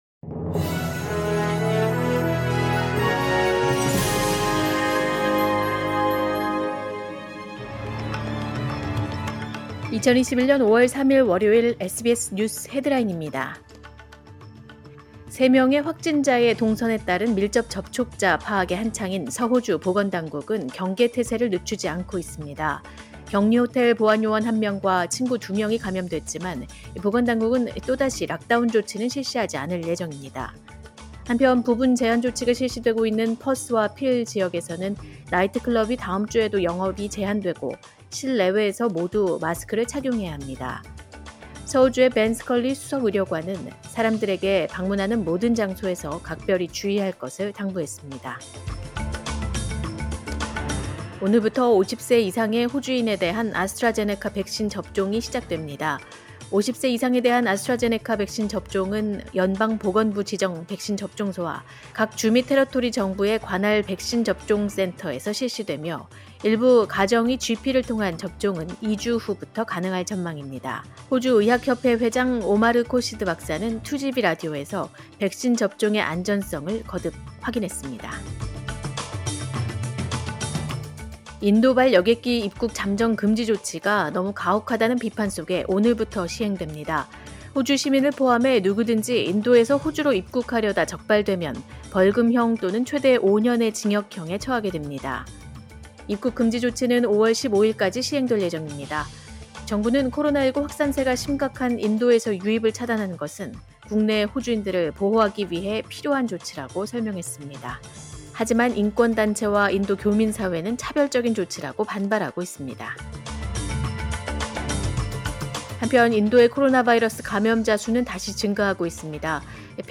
2021년 5월 3일 월요일 오전의 SBS 뉴스 헤드라인입니다.